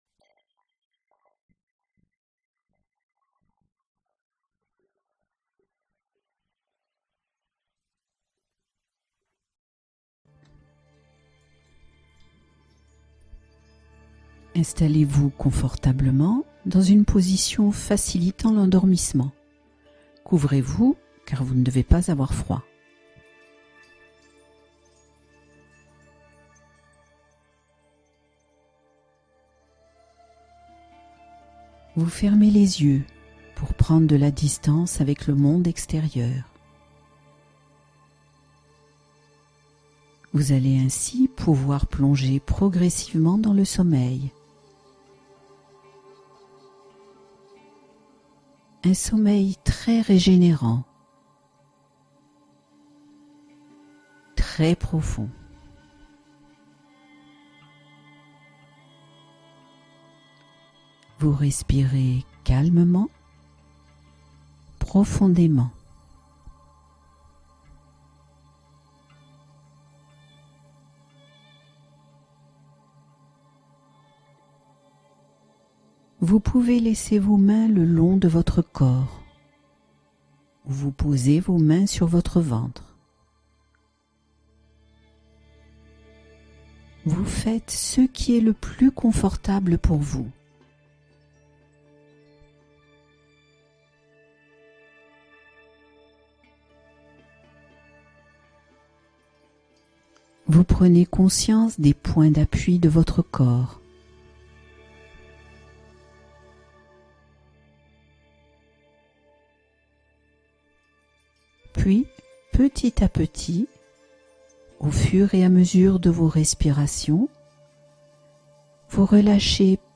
Glissez vers un sommeil réconfortant : la relaxation guidée préférée des anxieux